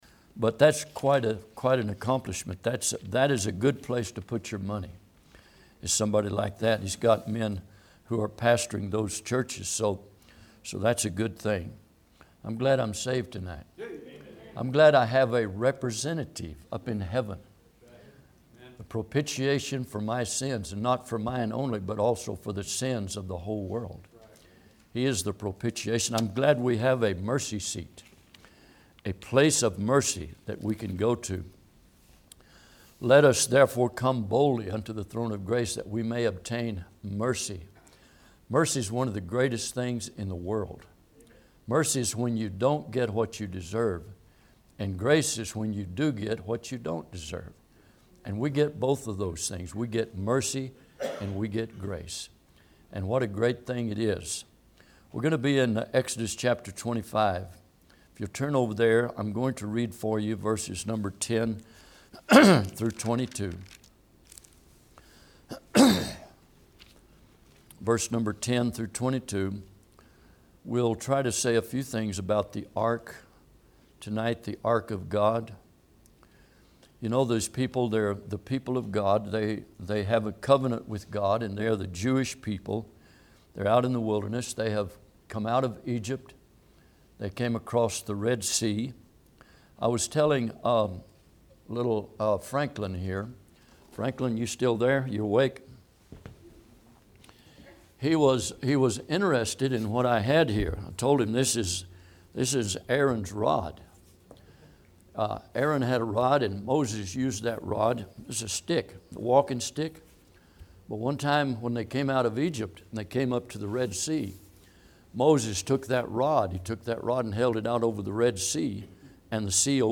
Service Type: Midweek